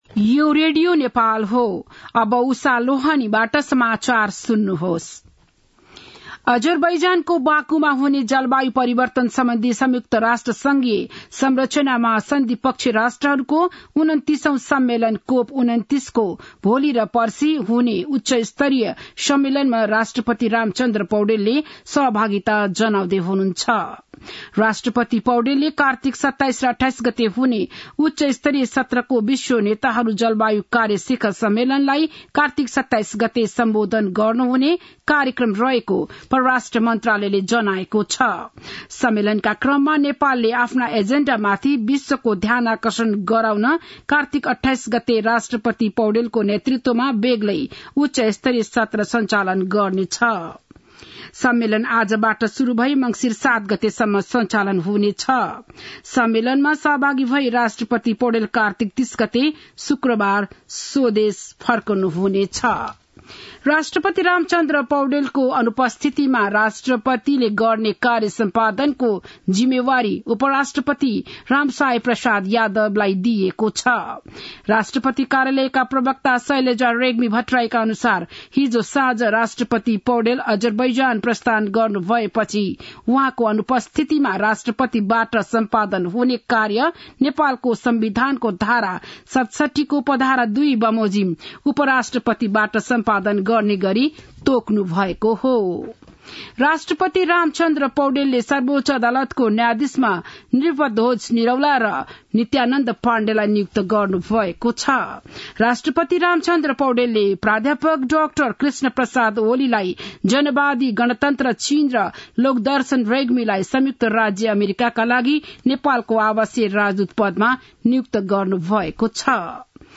बिहान ११ बजेको नेपाली समाचार : २७ कार्तिक , २०८१
11-am-news-1-1.mp3